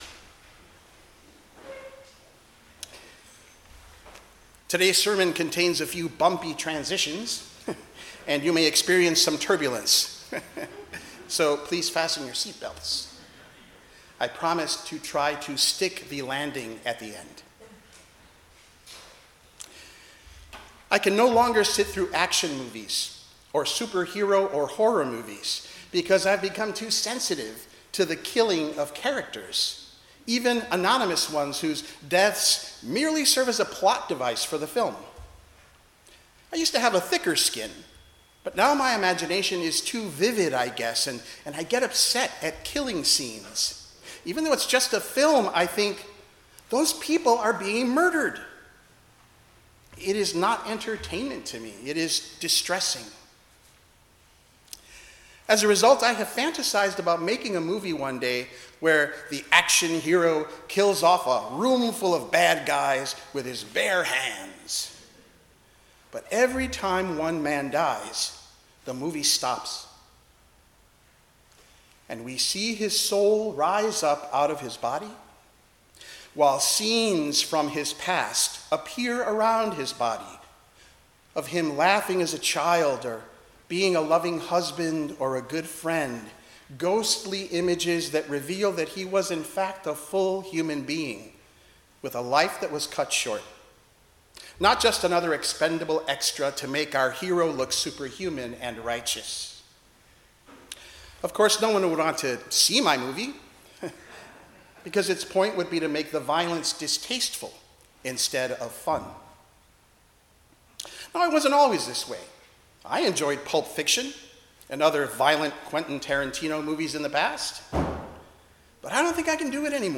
Passage: Deuteronomy 30:15-20, Psalm 1, Philemon 1-21, Luke 14:25-33 Service Type: 10:00 am Service